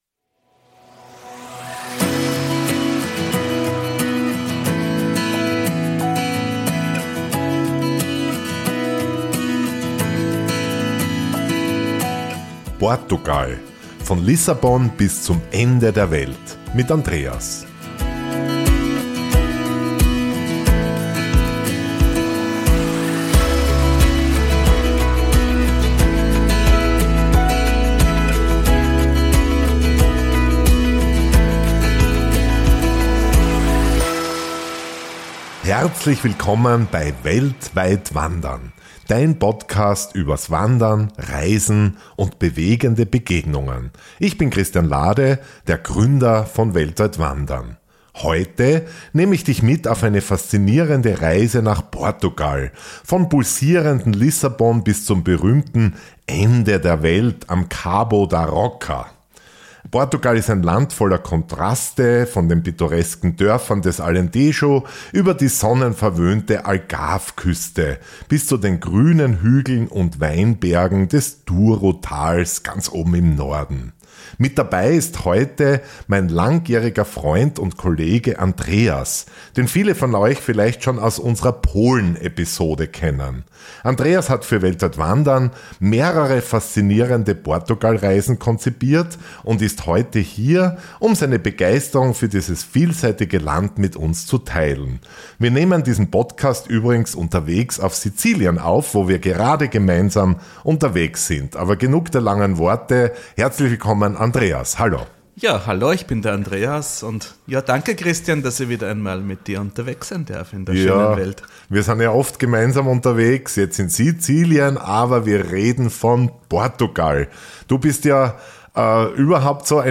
Aufgenommen unterwegs auf Sizilien, wo beide gerade gemeinsam mit Gästen wandern.